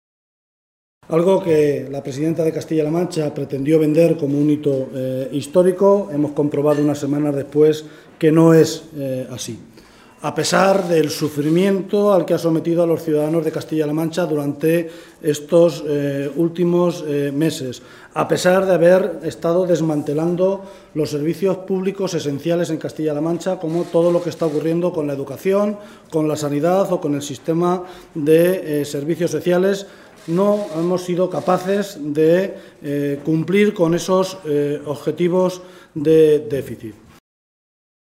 Guijarro se pronunciaba de esta manera esta tarde, en el Parlamento regional, en una comparecencia ante los medios de comunicación durante la celebración del Pleno de hoy, en la que valoraba los datos de déficit hechos públicos por el Ministerio de Hacienda, que señalan que nuestra región no ha cumplido el objetivo de déficit, al situarse por encima del 1,5 por ciento sobre el PIB exigido y dejarlo en el 1,53 por ciento.
Cortes de audio de la rueda de prensa